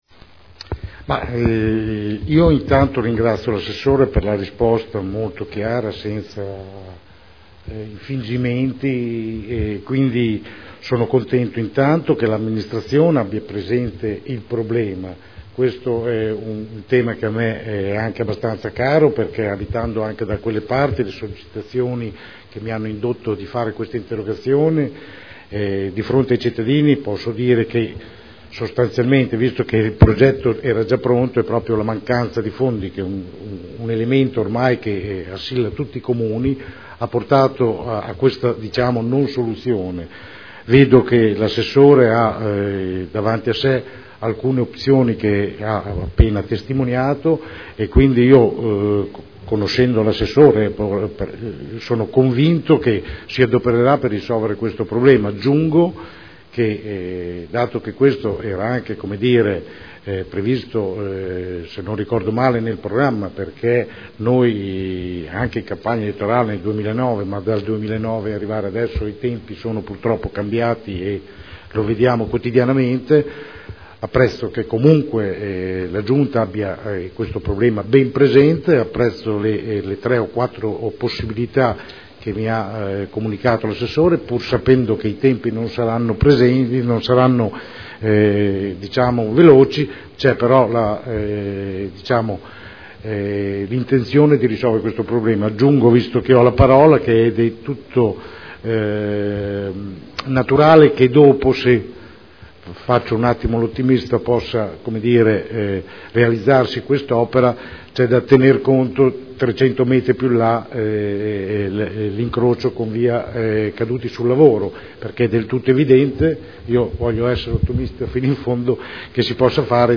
Francesco Rocco — Sito Audio Consiglio Comunale